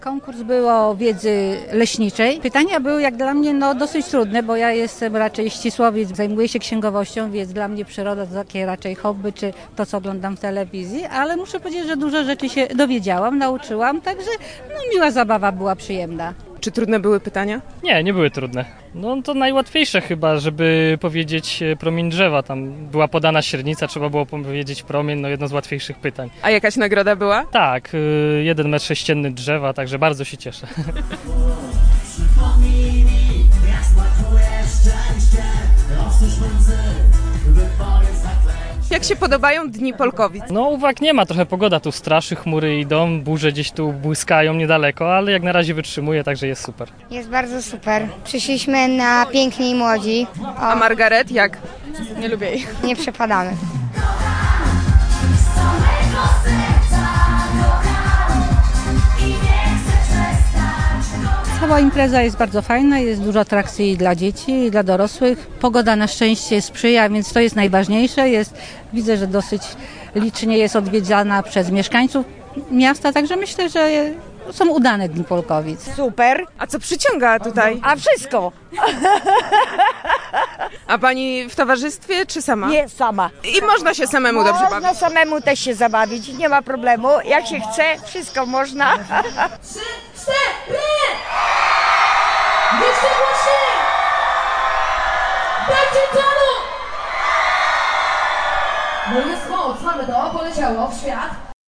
0620_relacja_dni_polkowic.mp3